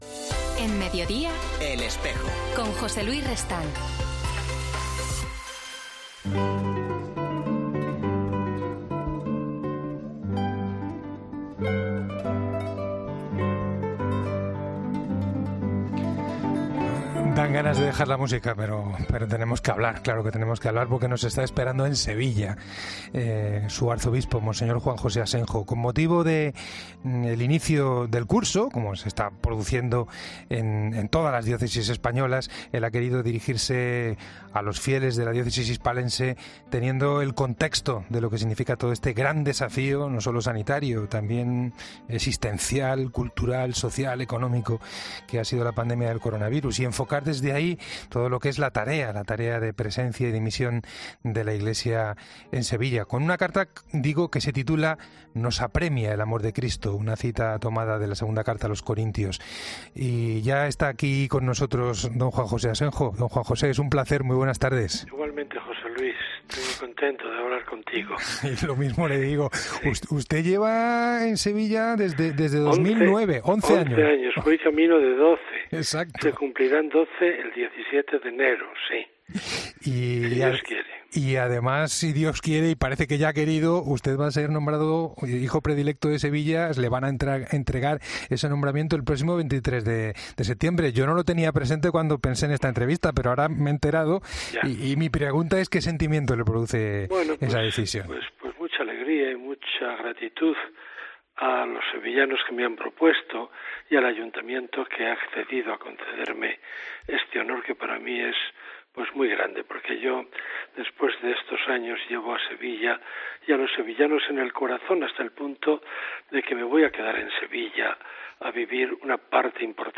El arzobispo de Sevilla ha explicado en 'El Espejo' los principales ejes sobre los que gira 'El amor de Cristo nos apremia'